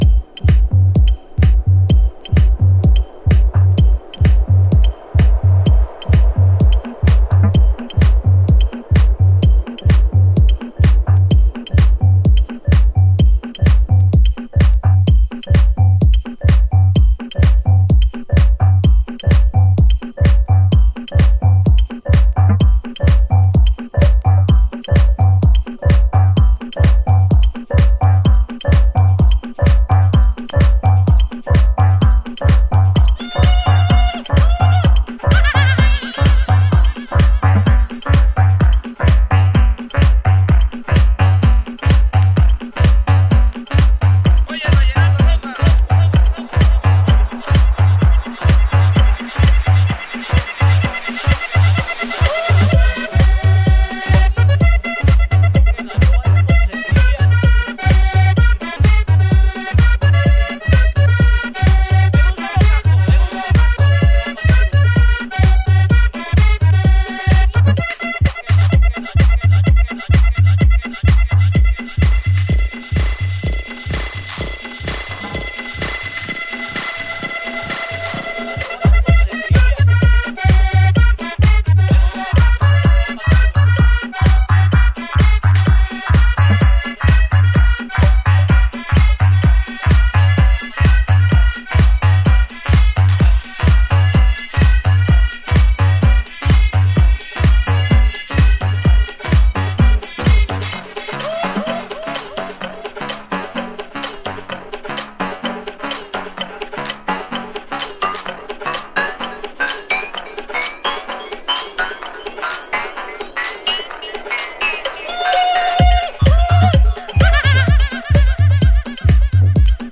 House / Techno
ネタはクンビアでしたっけ。